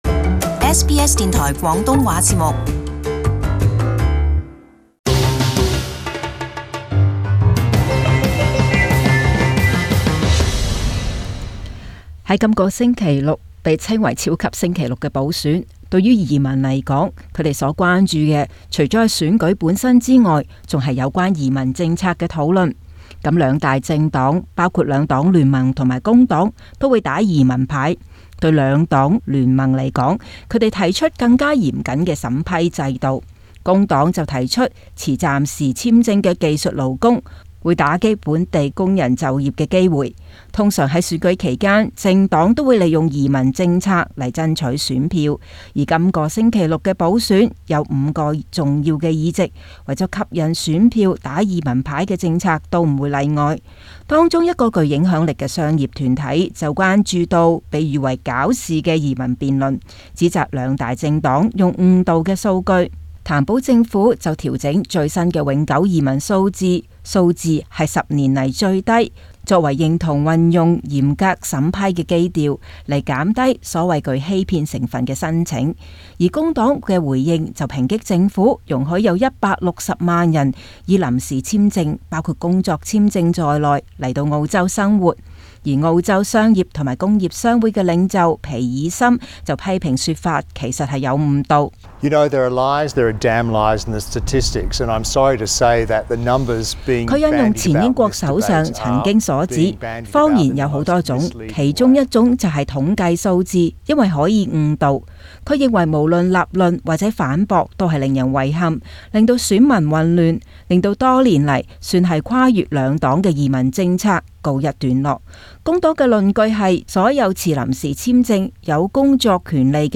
【時事報導】超級星期六選舉前的移民辯論